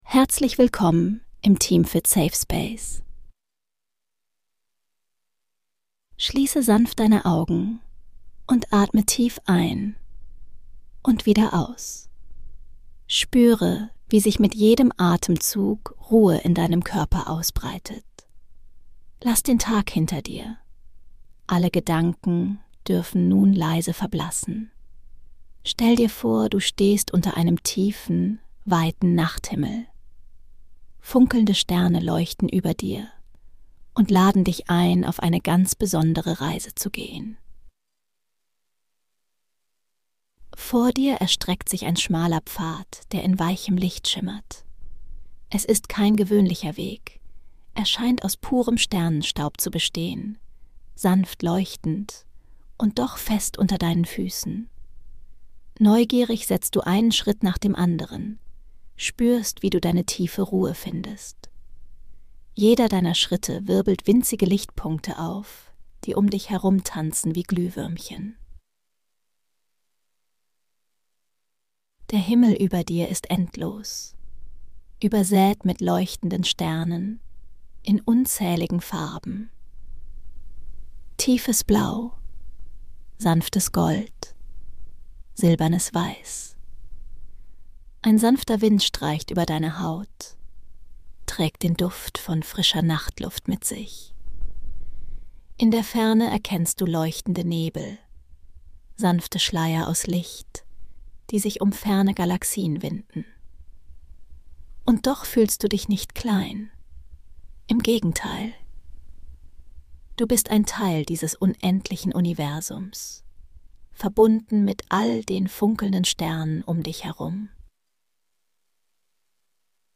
Eine meditative Reise durch das funkelnde Universum.